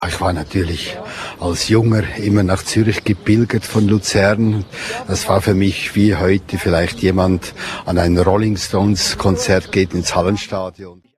P.S. Dass er immer noch für einen Lacher gut ist, bewies Altmeister Emil mit seinem
Statement.